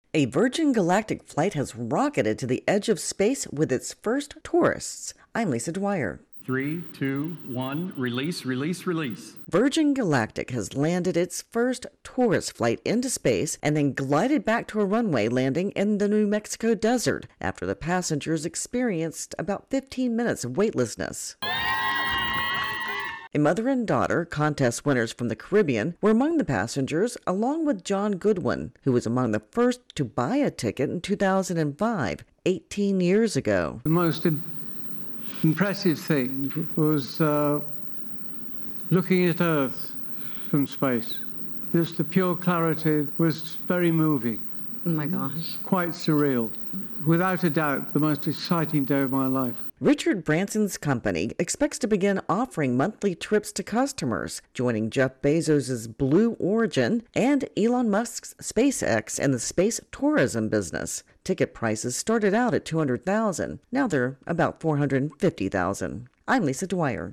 ((starts with nat sound)) SOUND COURTESY: Virgin Galactic